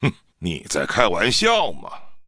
文件 文件历史 文件用途 全域文件用途 Enjo_skill_01_1.ogg （Ogg Vorbis声音文件，长度2.3秒，110 kbps，文件大小：30 KB） 源地址:地下城与勇士游戏语音 文件历史 点击某个日期/时间查看对应时刻的文件。